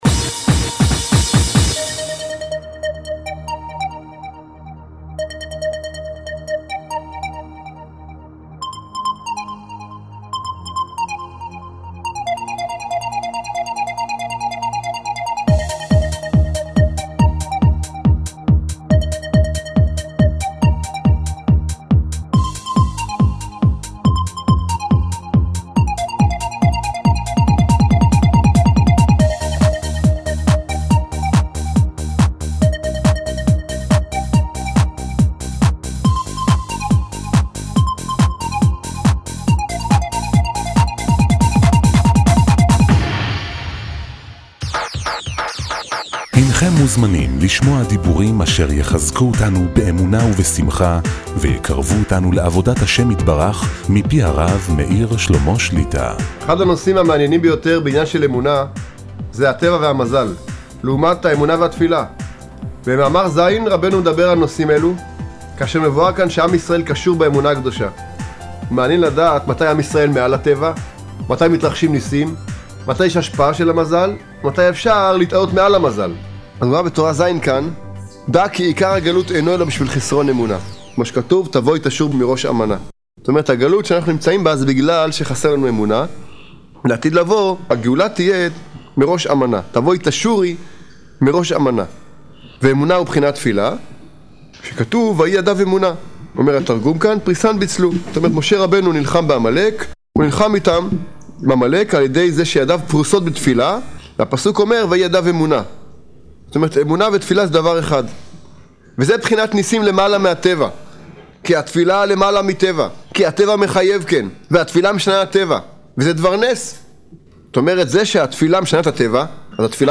שיעורי שמע